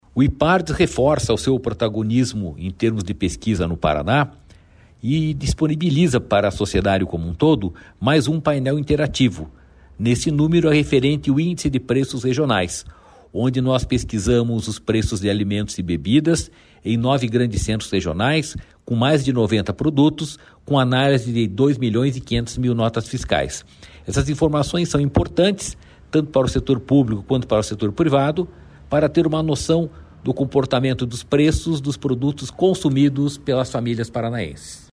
Sonora do diretor-presidente do Ipardes, Jorge Callado, sobre o painel aprimorado do IPR